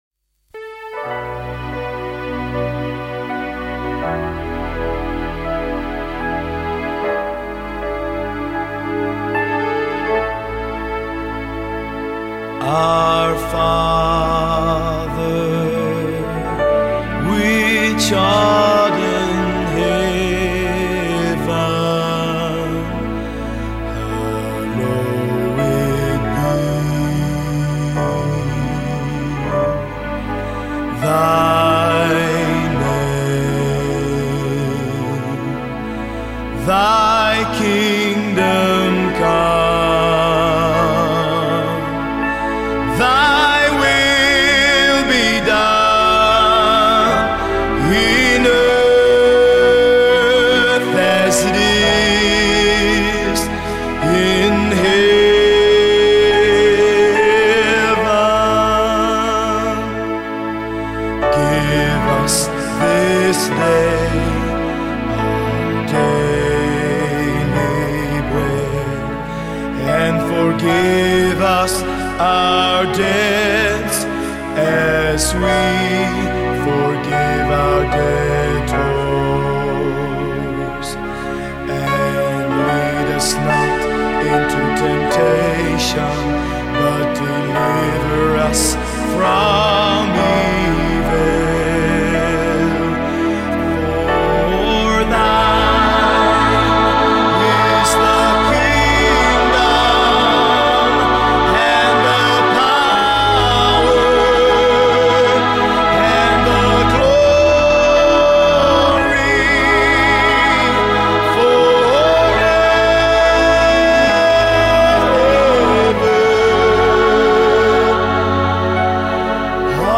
THE LORDS PRAYER SUNG